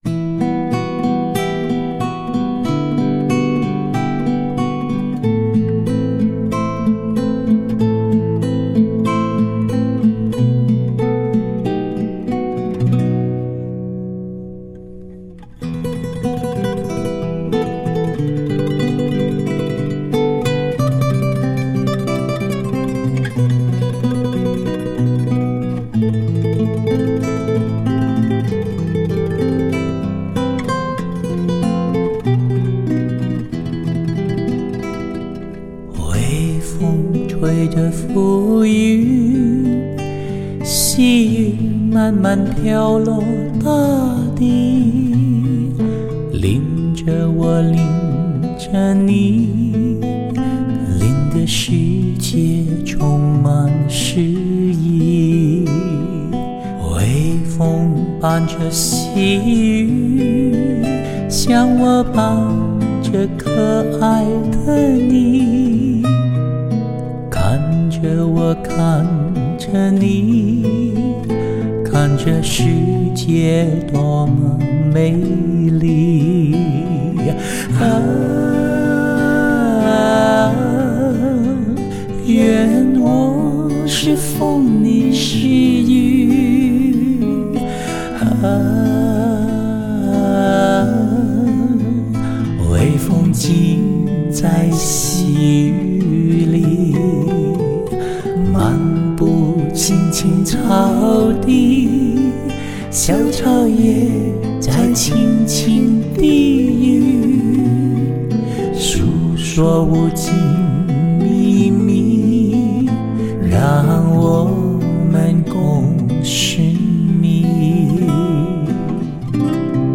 民歌味道…
在温柔蚀骨的歌声中
在高传真度的音效中